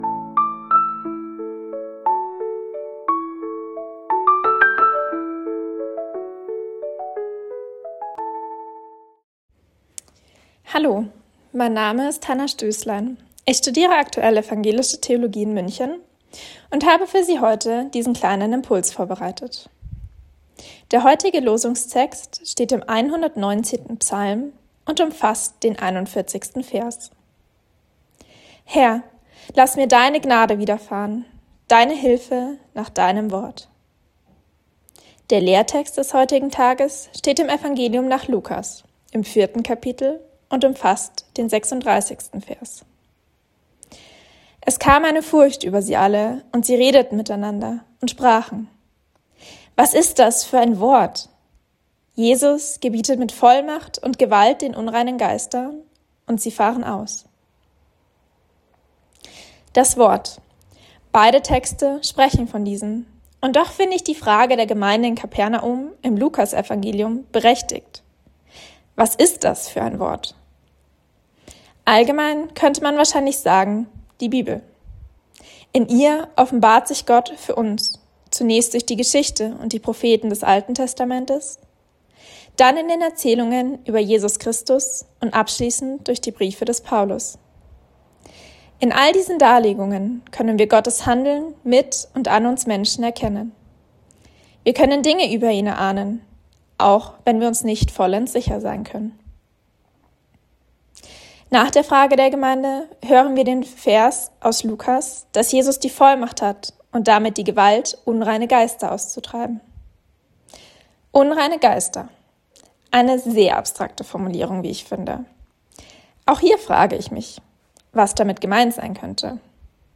Losungsandachten